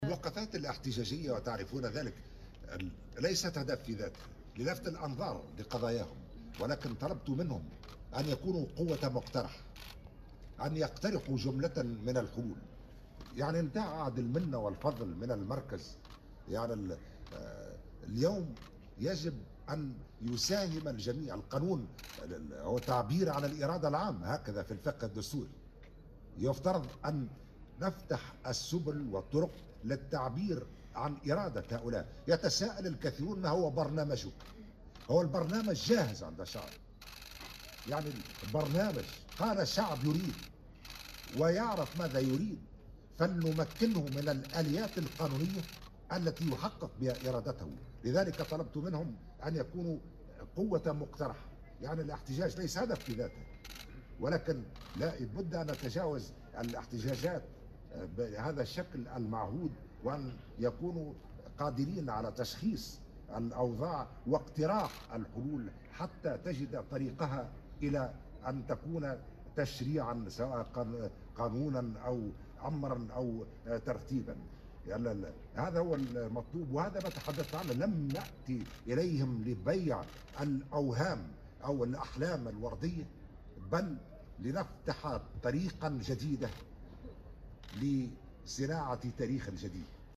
قال رئيس الجمهورية، قيس سعيّد على هامش زيارته اليوم لمدينة القيروان للإشراف على احتفالات المولد النبوي الشريف إنه التقى عددا من المعتصمين بمقر الولاية.